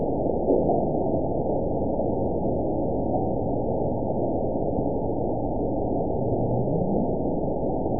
event 922649 date 02/26/25 time 21:19:55 GMT (3 months, 2 weeks ago) score 9.04 location TSS-AB04 detected by nrw target species NRW annotations +NRW Spectrogram: Frequency (kHz) vs. Time (s) audio not available .wav